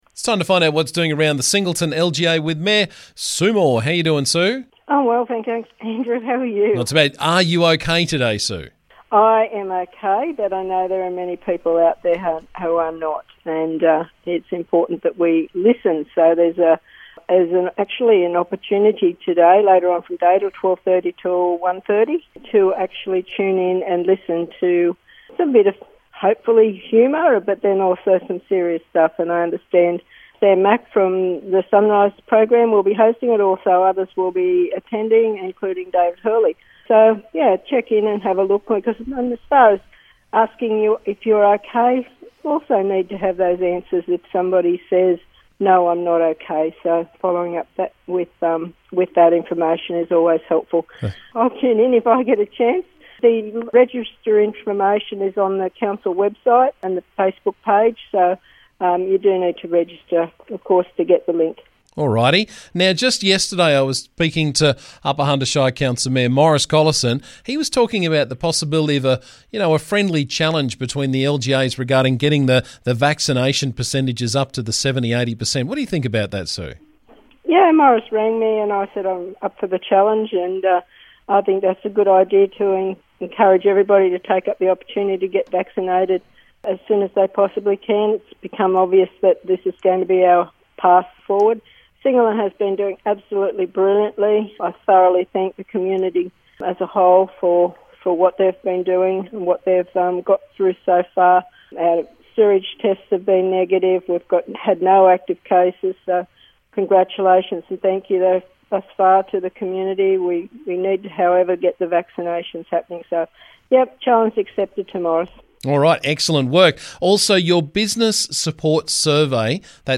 Every couple of weeks we catch up with Singleton Council Mayor Sue Moore to find out what's happening around the district.